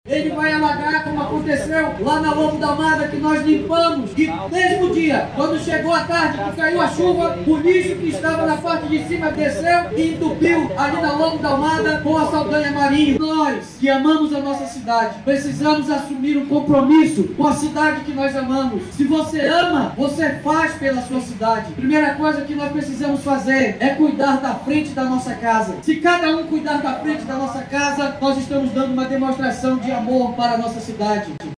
SONORA-2-DESCARTE-LIXO-.mp3